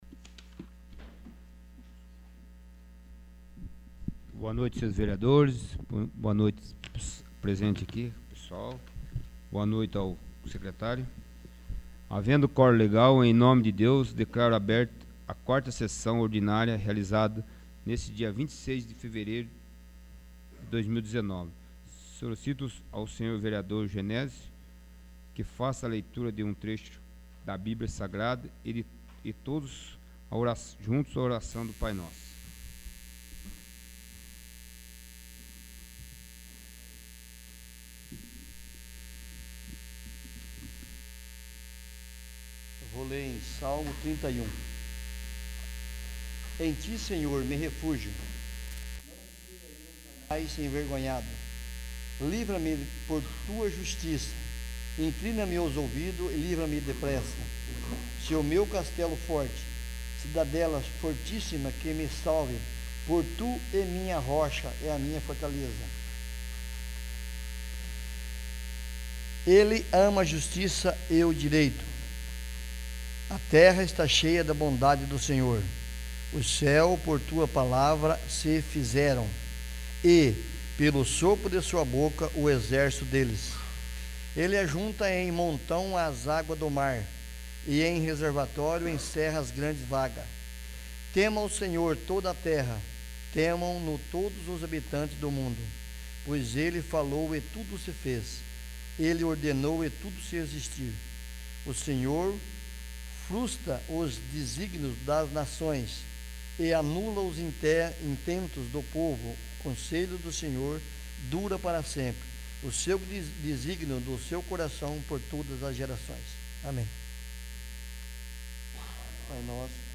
4º. Sessão Ordinária